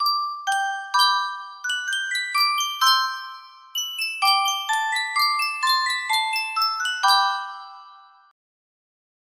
Sankyo Miniature Music Box - Robert Schumann Traumerei AT
Full range 60